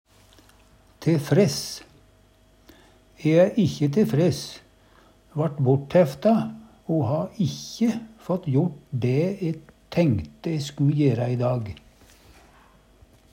te fress - Numedalsmål (en-US)